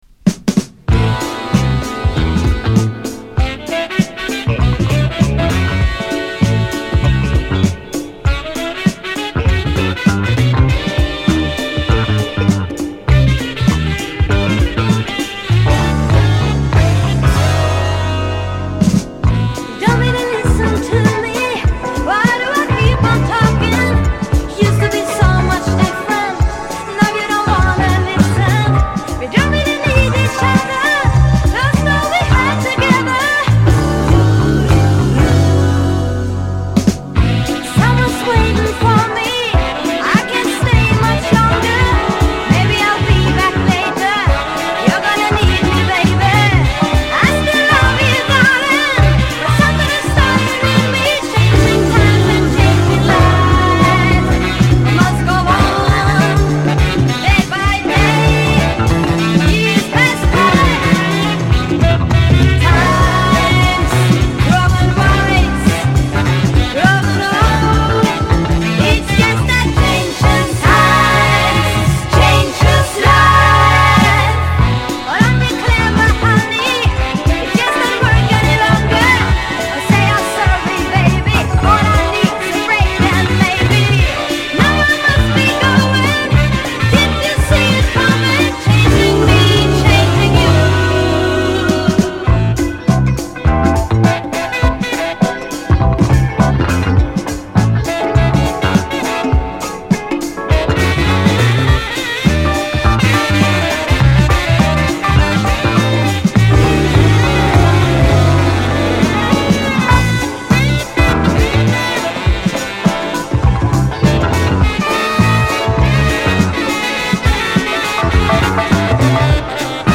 ソウルフルな歌声も魅力的。